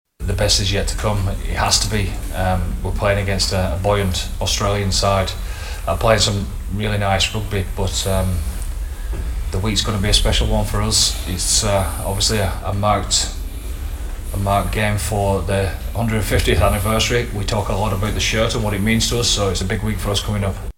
They finish their campaign against a resurgent Australia and Farrell says they will need to be better: